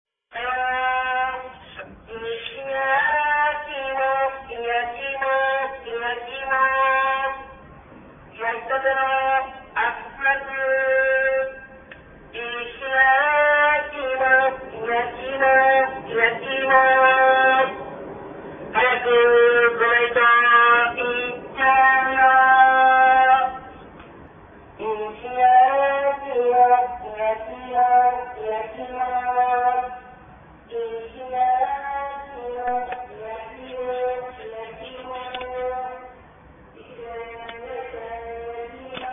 やきいも うるさい! Or bloody noisy Yakiimo van. – Blood and Soil
Yakiimo Song – is blasted through loud speakers/megaphones on top of the truck, very loud, totally annoying and disturbing what little peace and quiet we had to begin with.
yakiimo.mp3